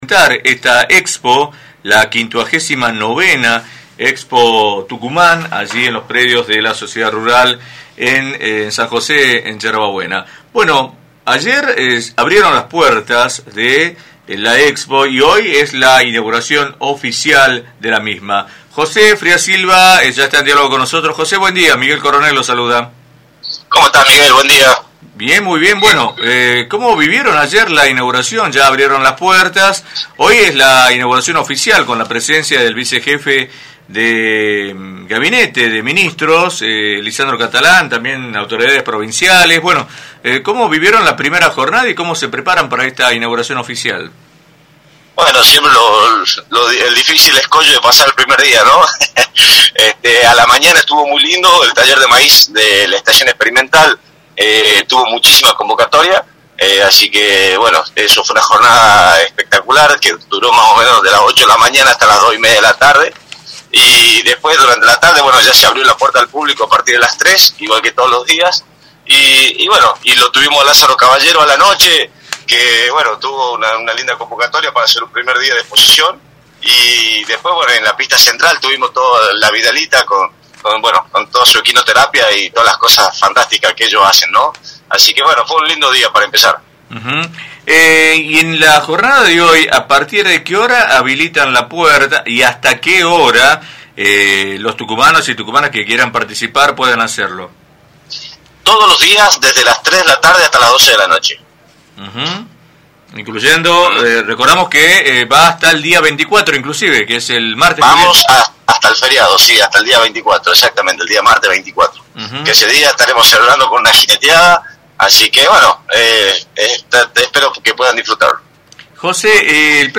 confirmó en entrevista con “6AM” que Osvaldo Jaldo y Lisandro Catalán estarán presentes en la inauguración de la Expo Tucumán 2024.